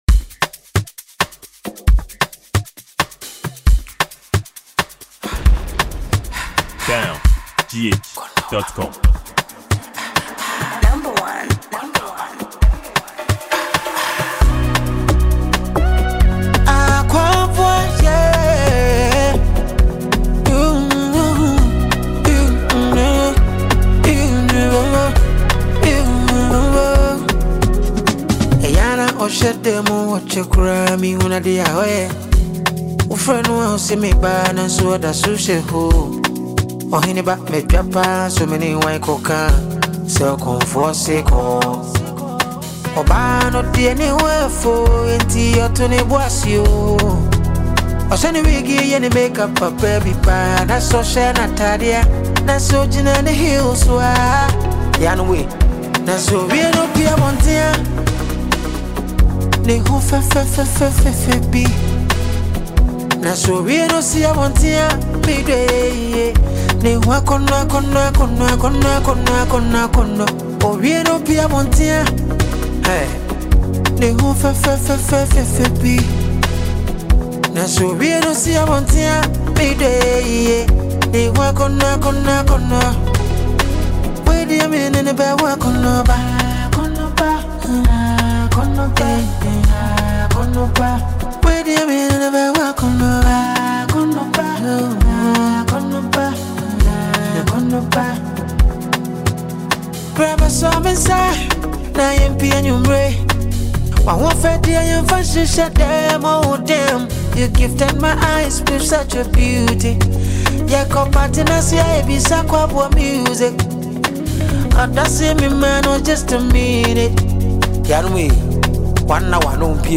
Songwriter, music producer and Ghanaian highlife singer
This is a pure lovers-rock jam song.